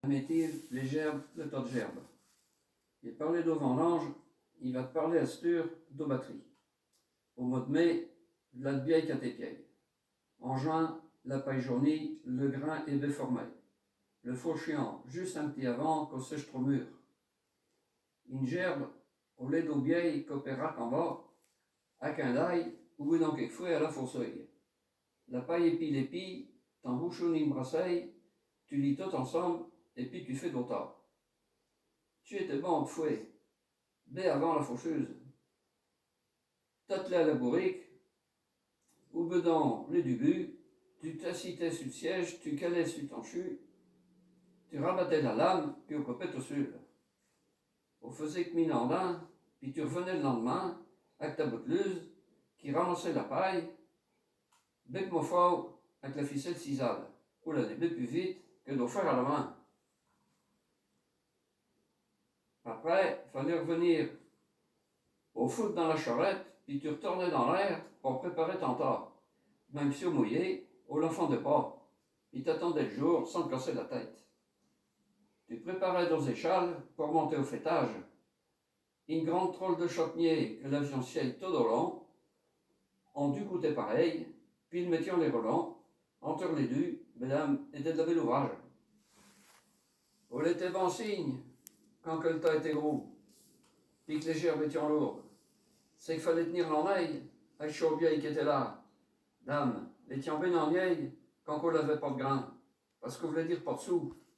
Poésies en patois